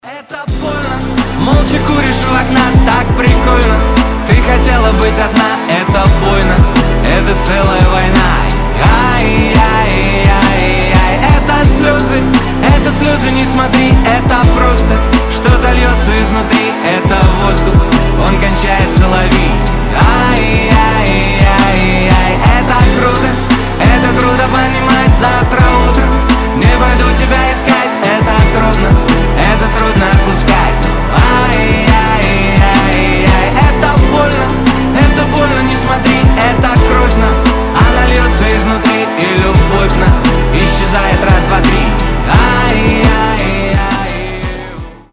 • Качество: 320, Stereo
душевные
грустные
красивый мужской вокал
сильные